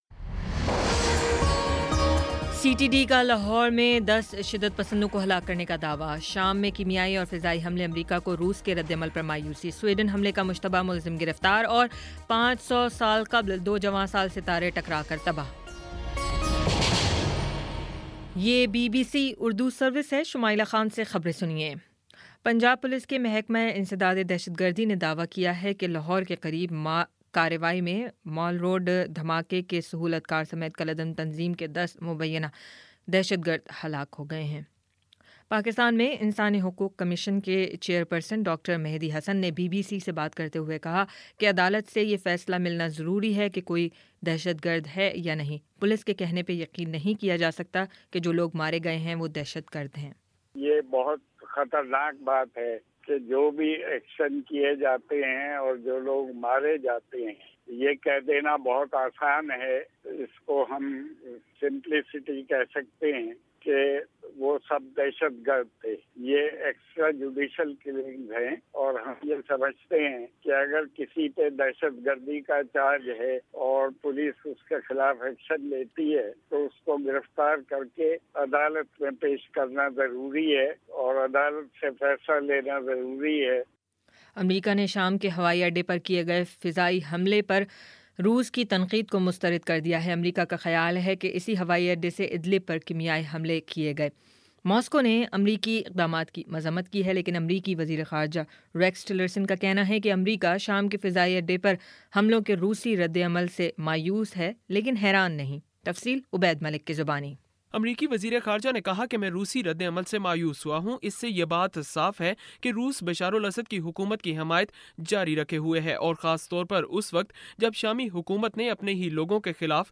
اپریل 08 : شام سات بجے کا نیوز بُلیٹن